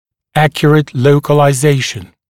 [‘ækjərət ˌləukəlaɪ’zeɪʃn][‘экйэрэт ˌлоукэлай’зэйшн]точная локализация, точное определение (место)положения